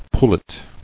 [pul·let]